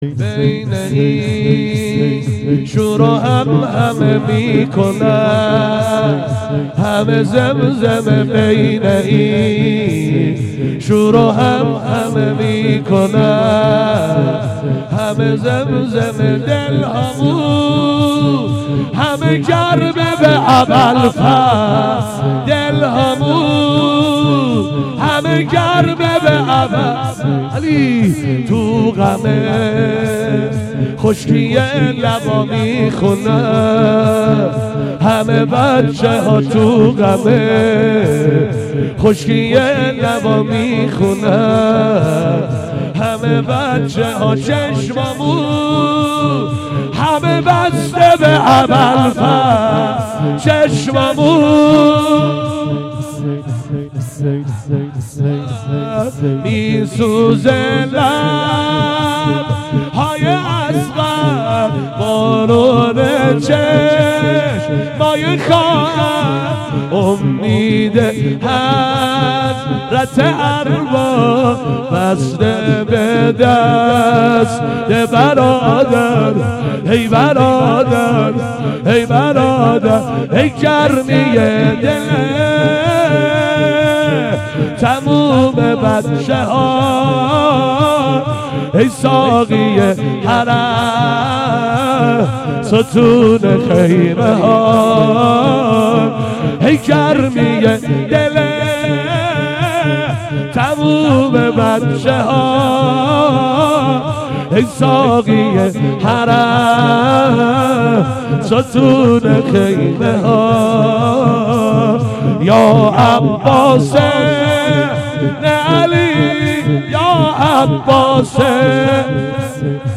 گلچین زمینه